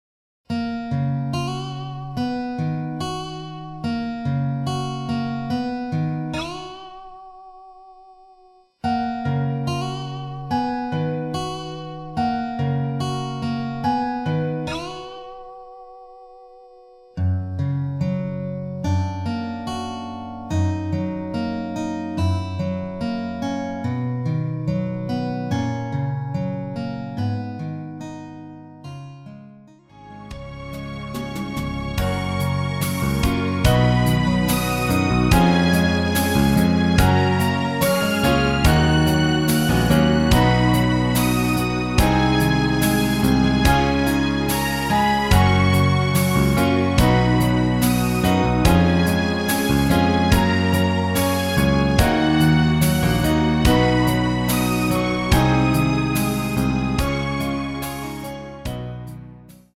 발매일 1998.02 키 F# 가수
원곡의 보컬 목소리를 MR에 약하게 넣어서 제작한 MR이며